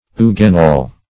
Search Result for " eugenol" : The Collaborative International Dictionary of English v.0.48: Eugenol \Eu"ge*nol\, n. [Eugenia + -ol.]